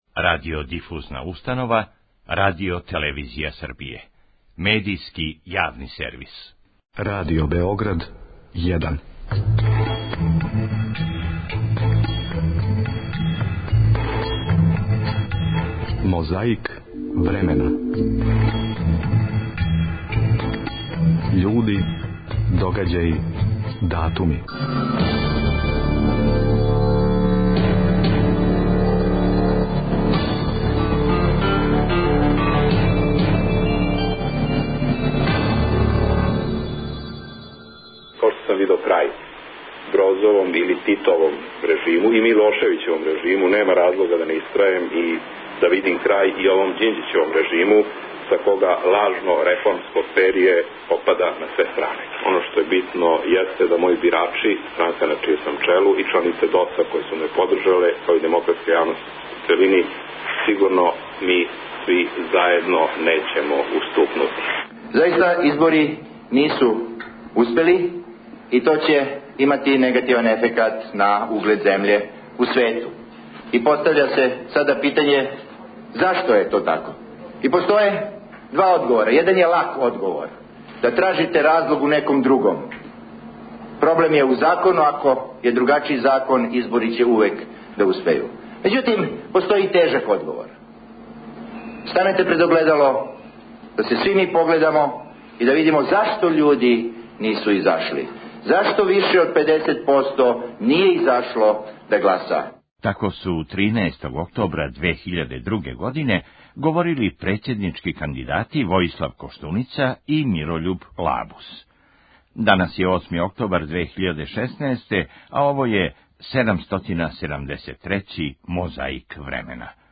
У београдском Медија центру 9. октобра 2002. године догодило се сучељавање председничких кандидата Војислава Коштунице и Мирољуба Лабуса.